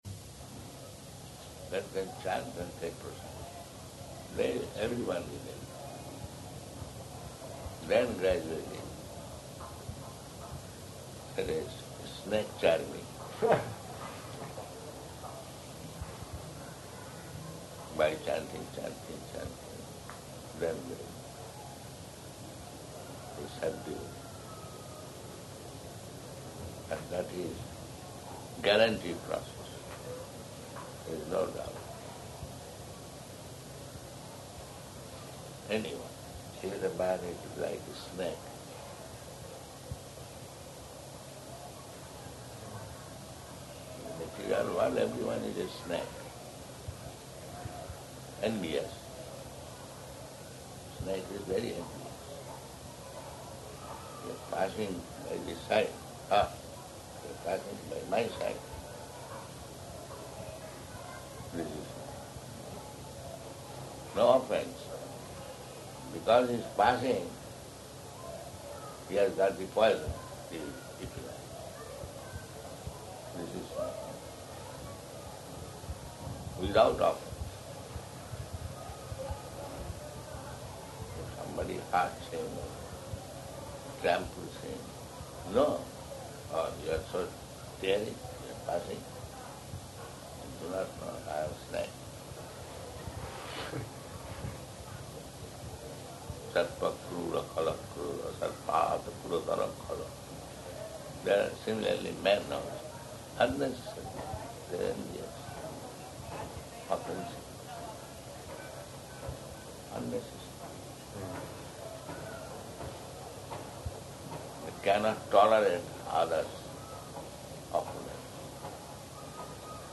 Room Conversation
Location: Bombay